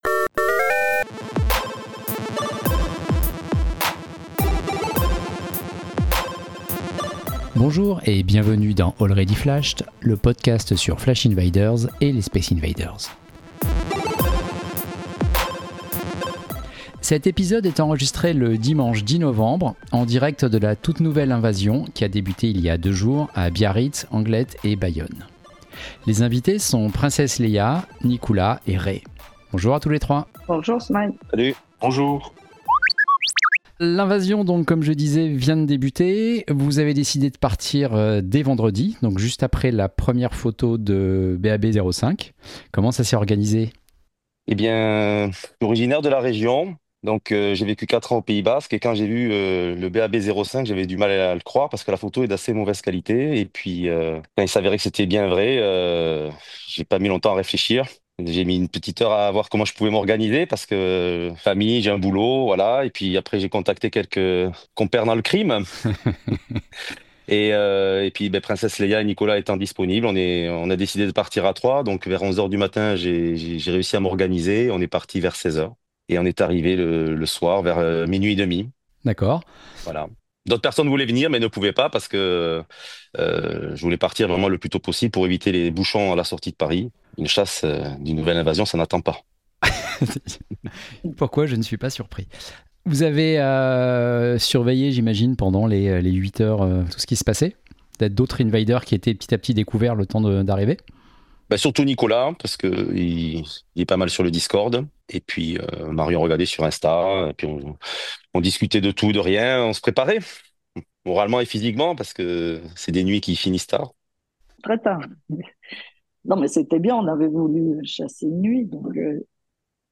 bruitages de l’application FlashInvaders, bruitages du jeu Galaga Namco 1981.